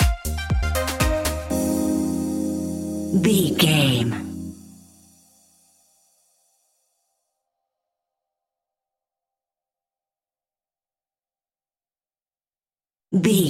Groovy Tropical Dance Stinger.
Aeolian/Minor
uplifting
energetic
funky
synthesiser
drum machine
electro house
synth leads
synth bass